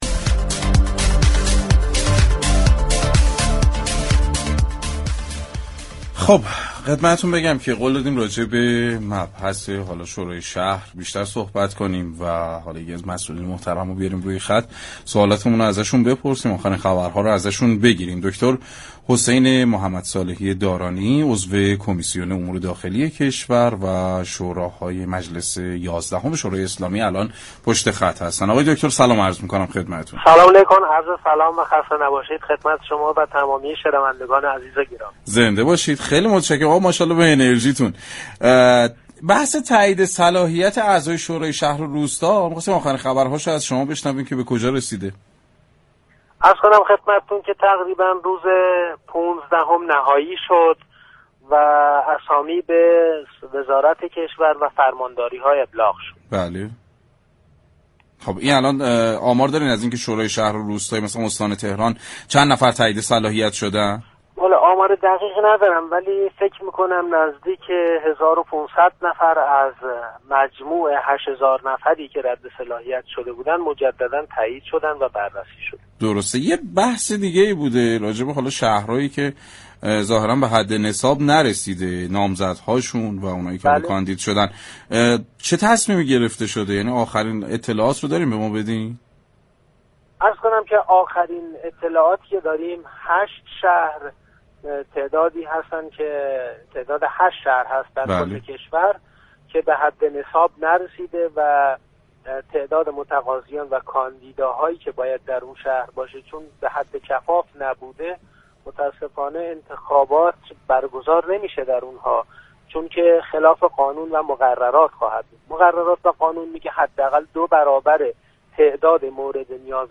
به گزارش پایگاه اطلاع رسانی رادیو تهران، حسین محمدصالحی دارانی عضو كمیسیون امور داخلی كشوردر گفتگو با سعادت آباد رادیو تهران درباره تایید صلاحیت نامزدهای شوراهای شهر و روستا گفت: 15 خردادماه لیست نهایی نامزدهای شوراها به وزارت كشور و فرمانداری ها ابلاغ شد.